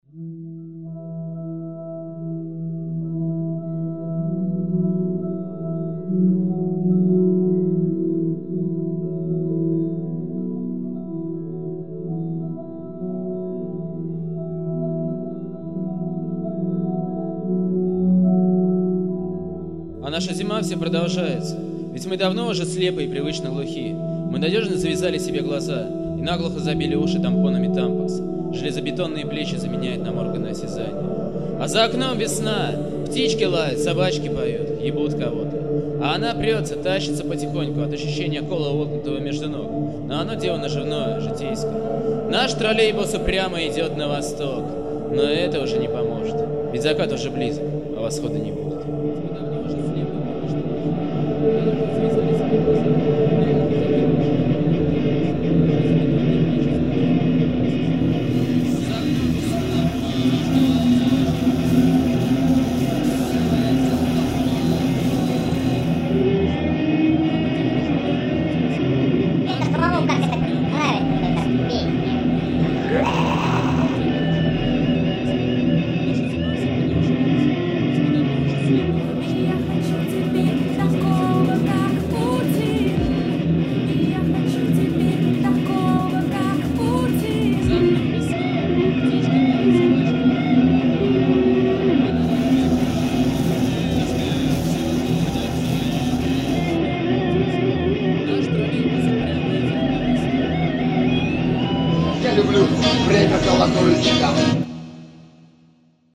Панк-рок, местами с элементами психоделии.